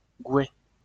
Gouaix (French pronunciation: [ɡwɛ]